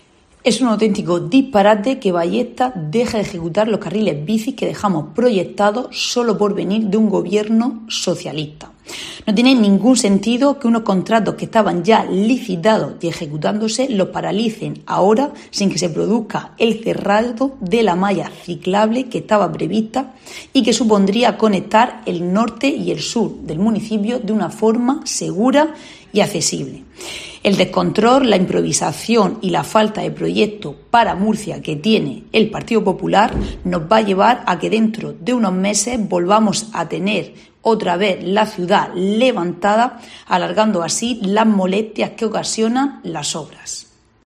Carmen Fructuoso, concejal del PSOE en el Ayuntamiento de Murcia